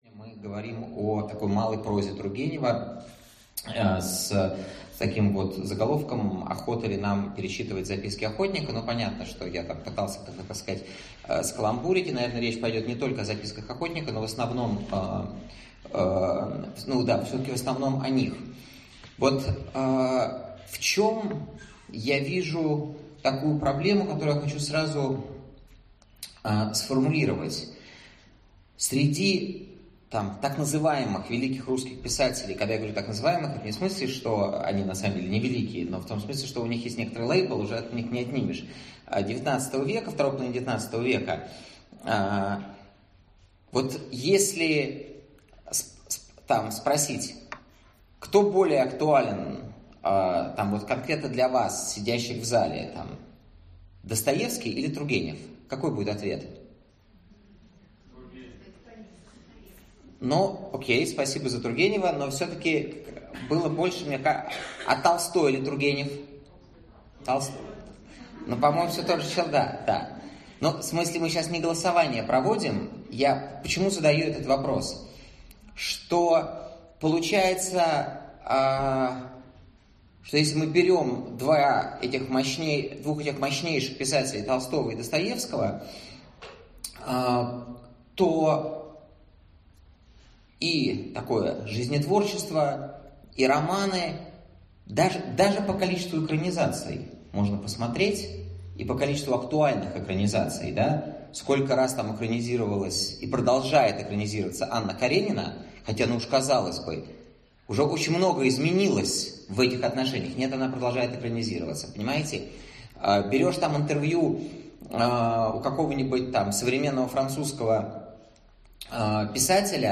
Аудиокнига Охота ли нам перечитывать «Записки охотника» | Библиотека аудиокниг